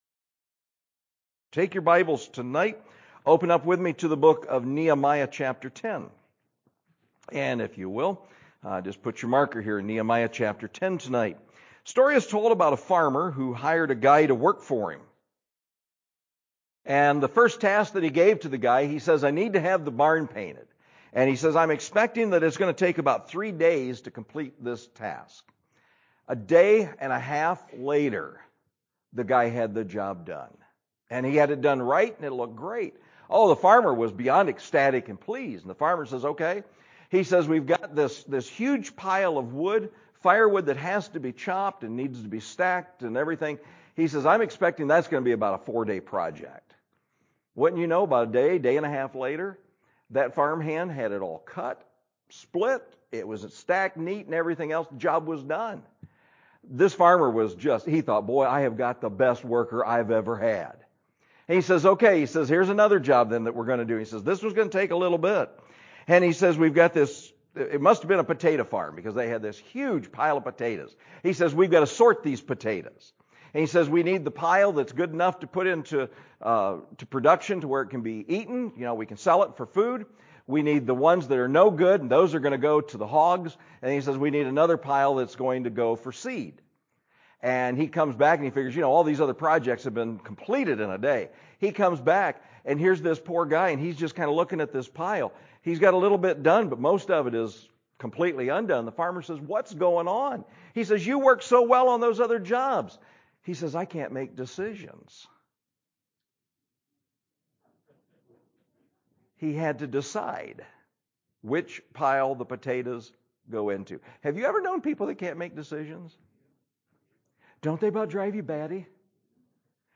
Decision Time – III – MIDWEEK – 12/1/21